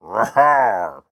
snapshot / assets / minecraft / sounds / mob / pillager / celebrate2.ogg
celebrate2.ogg